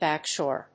back+shore.mp3